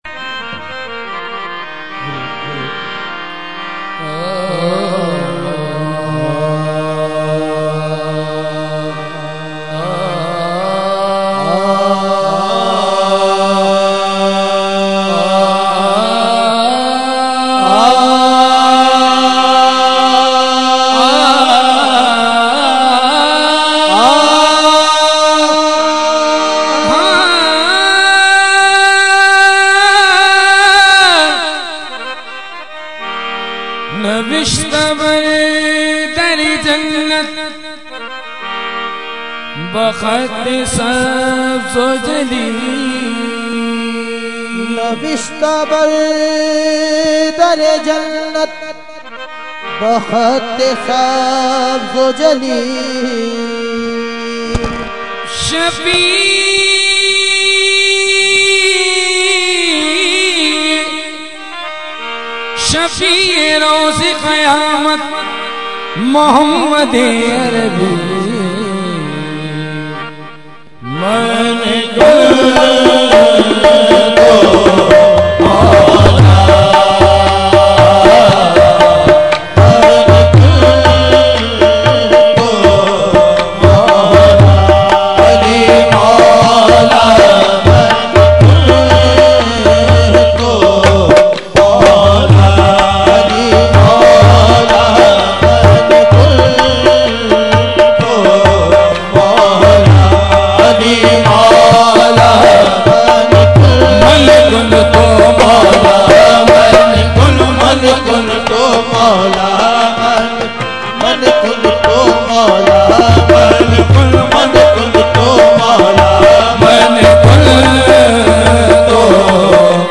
Category : Qawali | Language : UrduEvent : Urs Qutbe Rabbani 2012